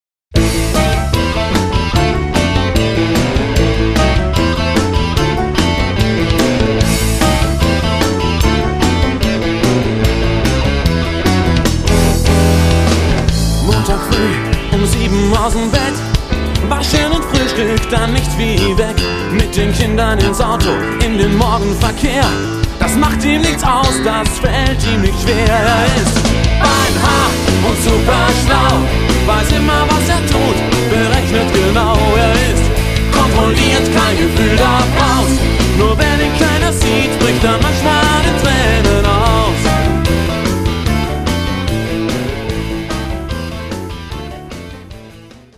Pop mit deutschen Texten